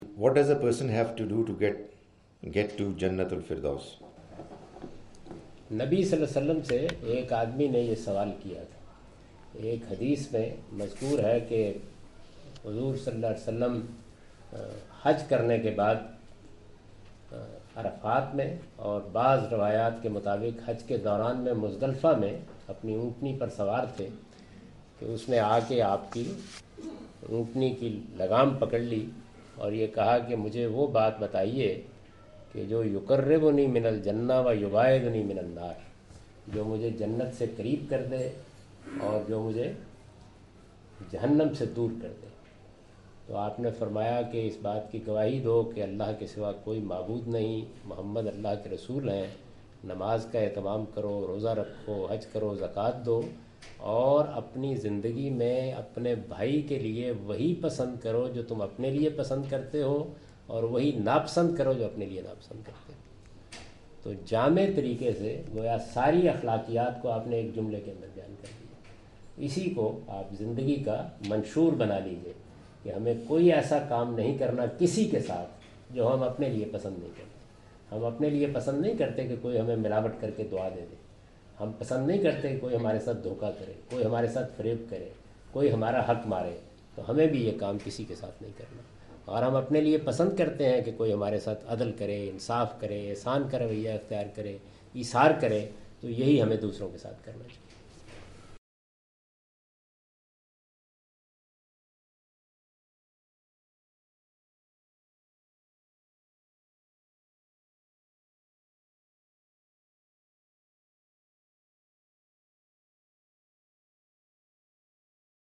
Javed Ahmad Ghamidi answer the question about "merits to enter jannah" during his visit to Manchester UK in March 06, 2016.
جاوید احمد صاحب غامدی اپنے دورہ برطانیہ 2016 کے دوران مانچسٹر میں "جنت میں داخلے کے لیے کونسے اعمال ضروری ہیں؟" سے متعلق ایک سوال کا جواب دے رہے ہیں۔